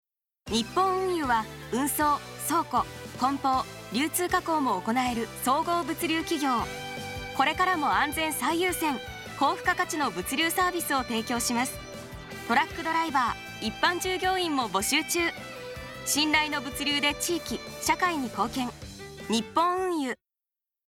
2025年5月2日より、FM NACK5（79.5MHz）にて、当社のラジオCMの放送が始まりました。